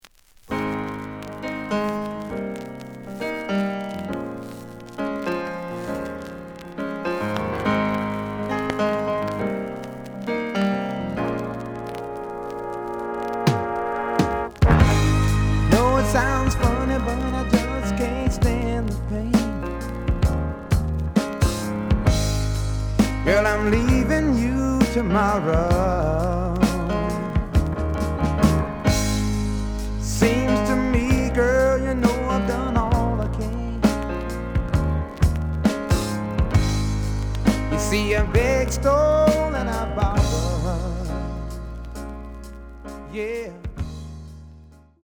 The audio sample is recorded from the actual item.
●Genre: Soul, 70's Soul
Some noise on beginnig of A side.